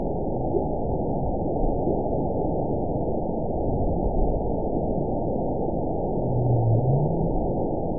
event 917614 date 04/10/23 time 05:03:57 GMT (2 years, 1 month ago) score 9.42 location TSS-AB01 detected by nrw target species NRW annotations +NRW Spectrogram: Frequency (kHz) vs. Time (s) audio not available .wav